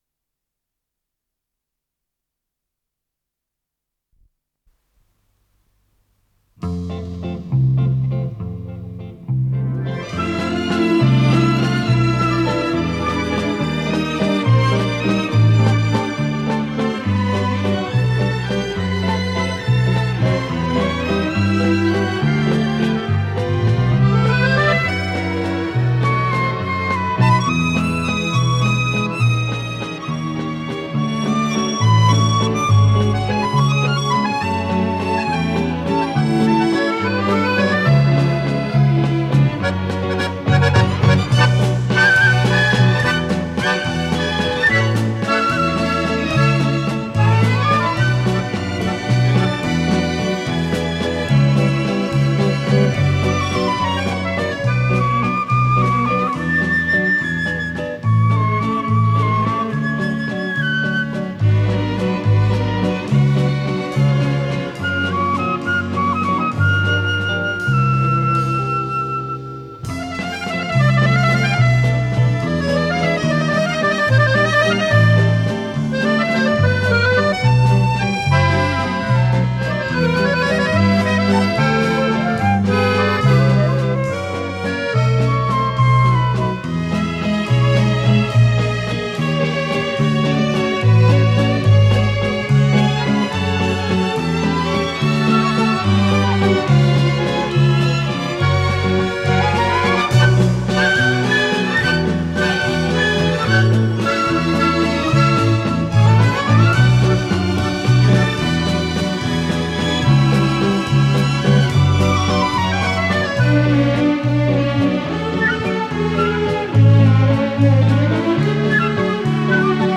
с профессиональной магнитной ленты
ПодзаголовокФа мажор
ВариантДубль моно